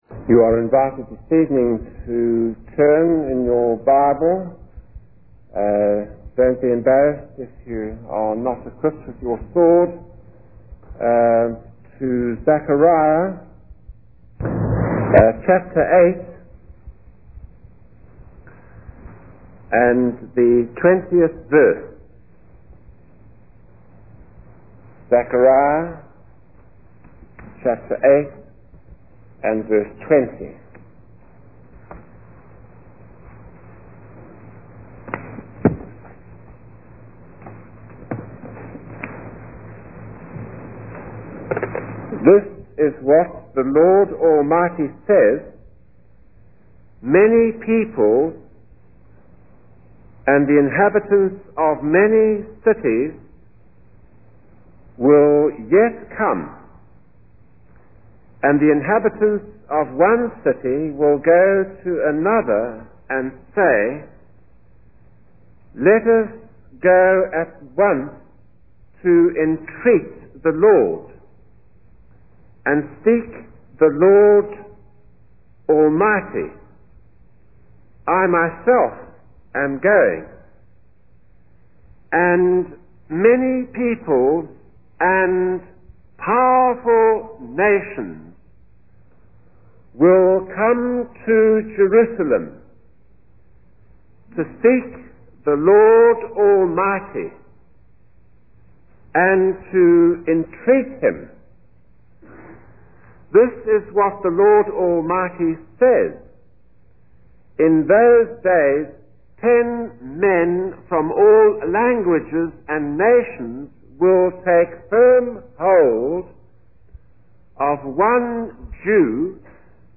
In this sermon, the speaker emphasizes the responsibility of believers to fulfill the Great Commission as stated in Matthew 28.